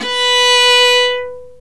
Index of /90_sSampleCDs/Roland - String Master Series/STR_Viola Solo/STR_Vla3 _ marc
STR VIOLA 0C.wav